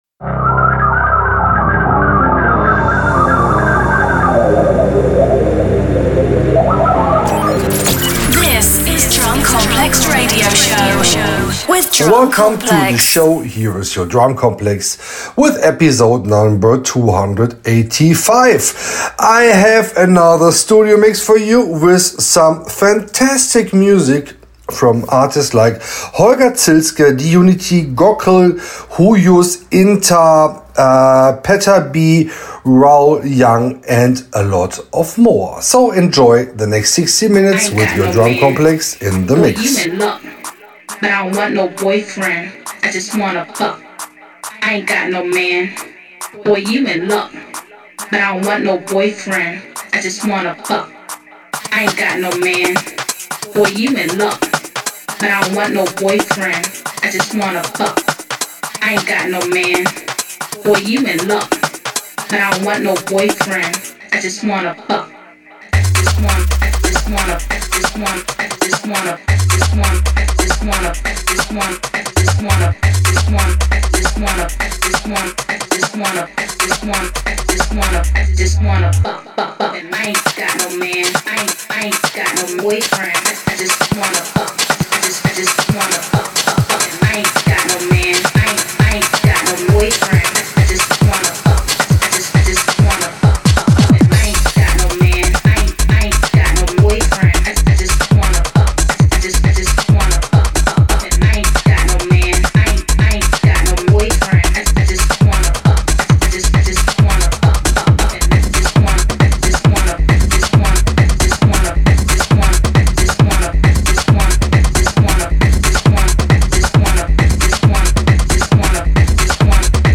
Also find other EDM Livesets, DJ Mixes and Radio Show